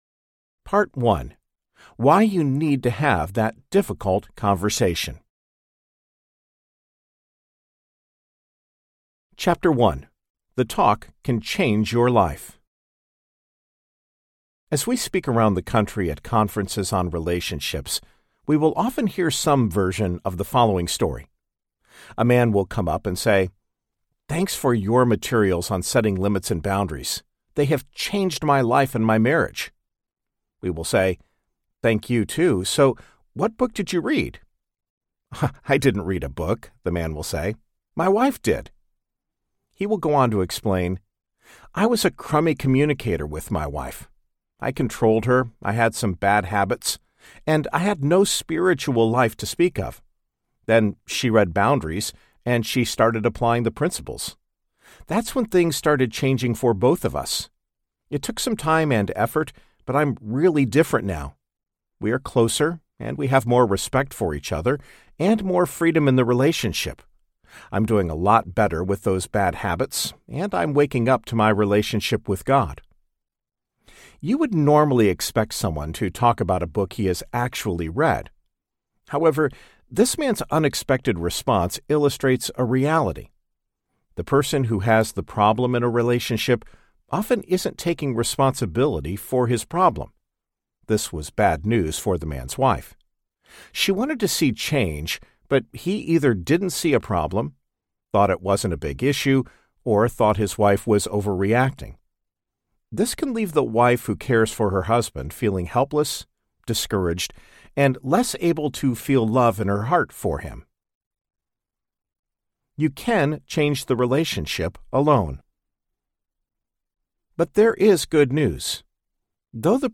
How to Have That Difficult Conversation Audiobook
Narrator
9.7 Hrs. – Unabridged